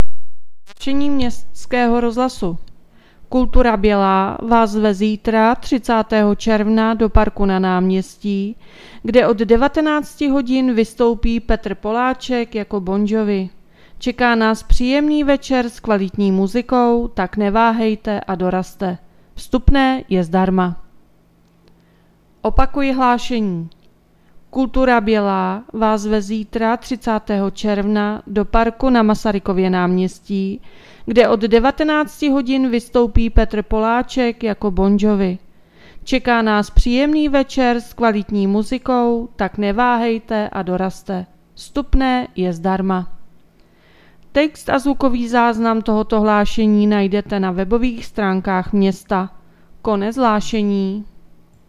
Hlášení městského rozhlasu 29.7.2024